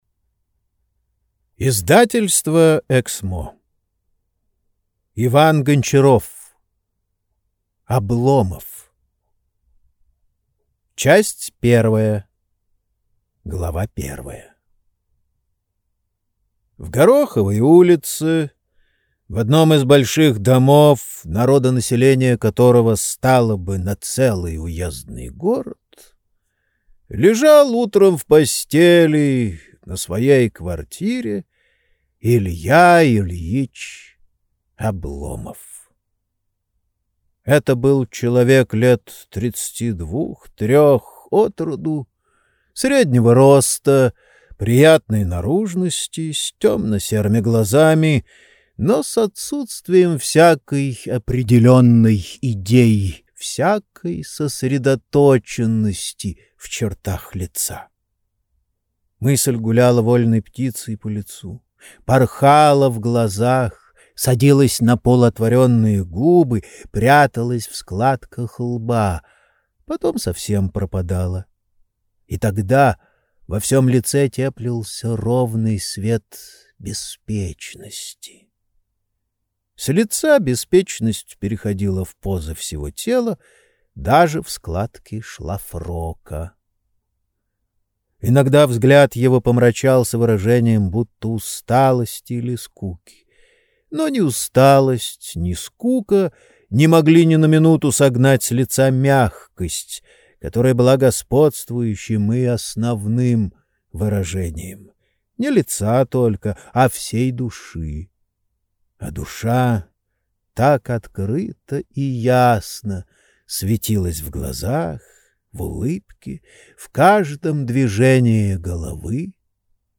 Аудиокнига Обломов | Библиотека аудиокниг